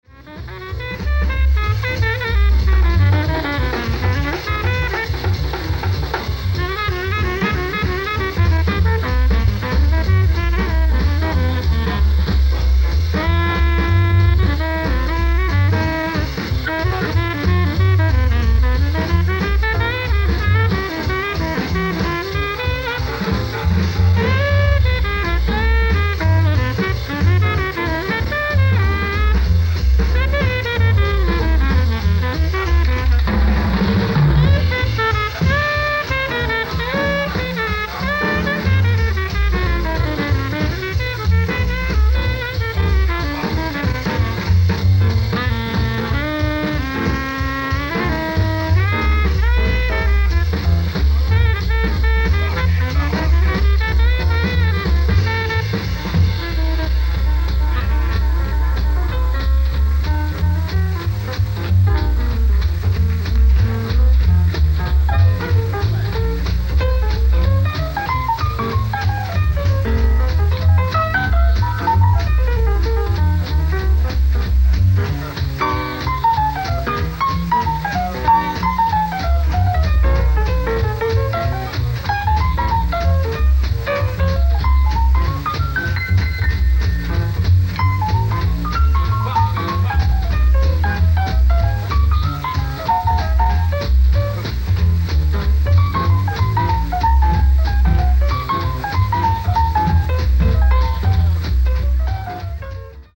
ライブ・アット・フリーボディー・パーク、ロードアイランド
※試聴用に実際より音質を落としています。